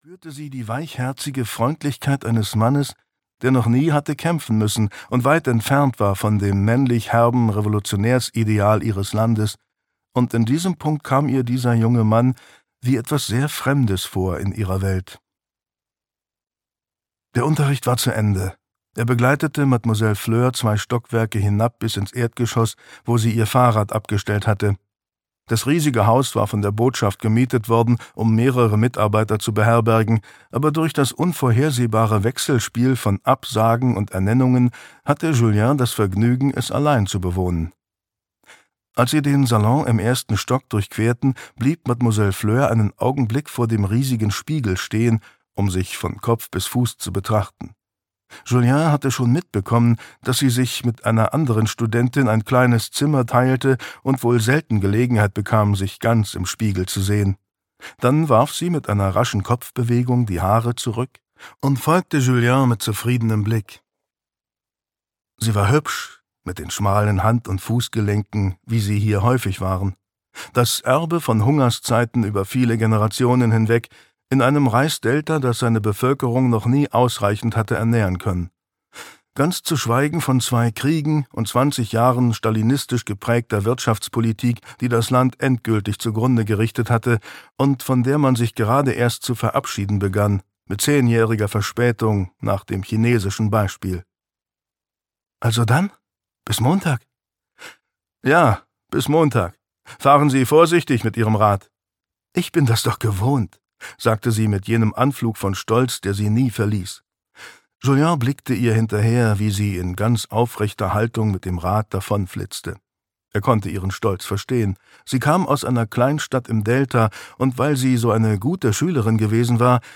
Die kleine Souvenirverkäuferin - François Lelord - Hörbuch